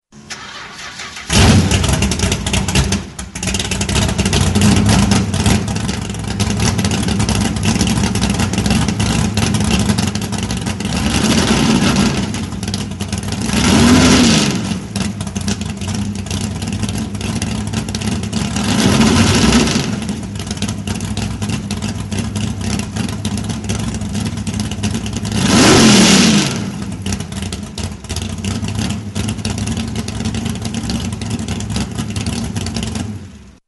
Trophy Truck